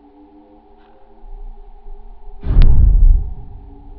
Sounds: Clipped raw sound (SHRU SW49) and a non-clipped sound (ch22 on HLA had lower sensitivity) of the explosion. To really hear the lower frequencies, a sub-woofer is necessary.
adcp_explosion_noclip.wav